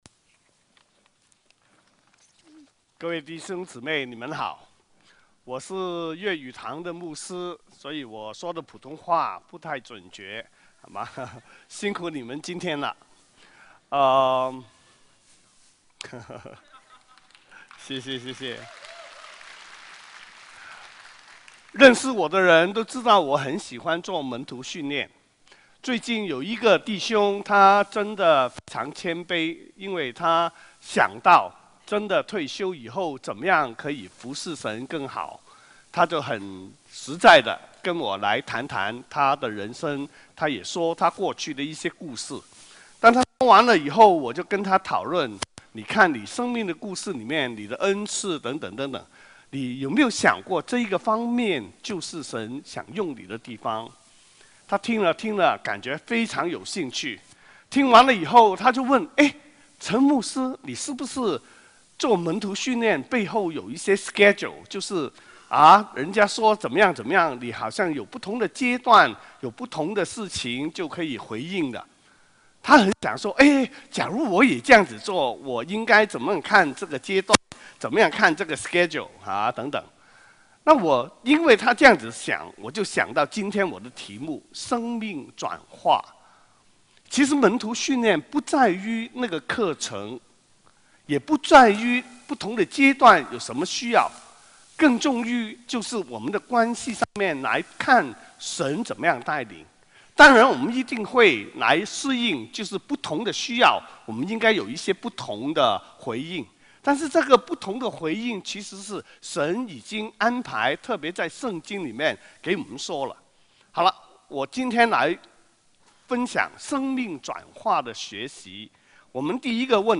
主日证道 |  生命转化中学习